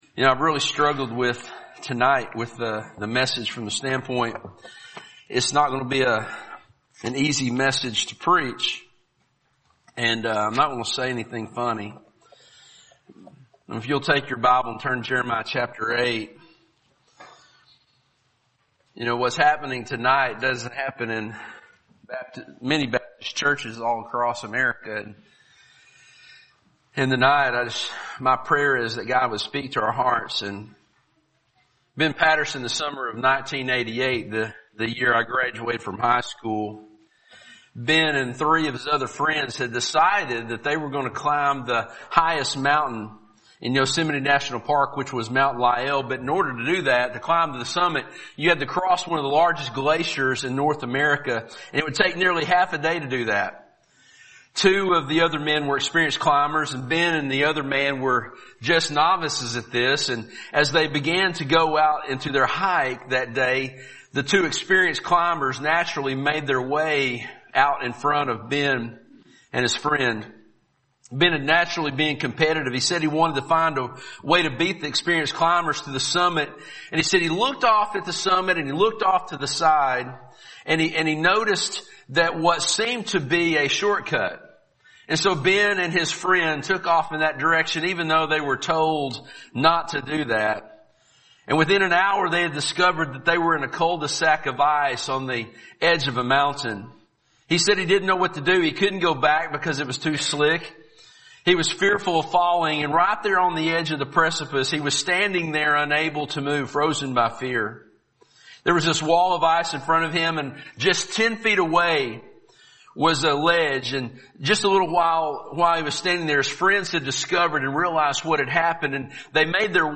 Fall Revival 2019 – Sermon 2
Revival Service Type: Revival Preacher